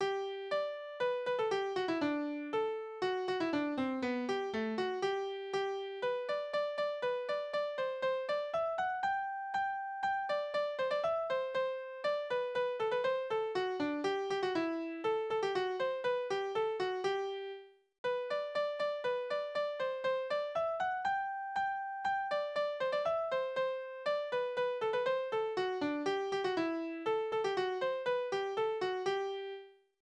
« 10958 » Scherenschleifer Spottlieder auf Berufe Tonart: G-Dur Taktart: 2/4 Tonumfang: Oktave, kleine Septime Besetzung: instrumental Externe Links: Sprache: hochdeutsch eingesendet von Aug.